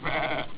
Lamb
LAMB.wav